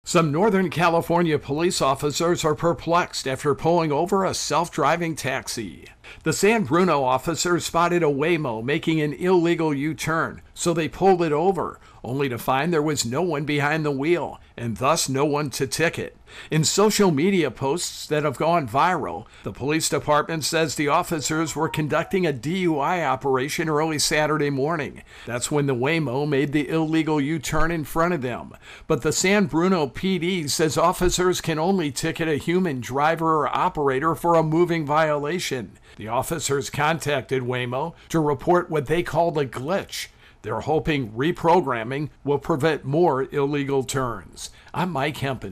A taxi is pulled over for a driving violation, but there's no human driver to ticket. AP correspondent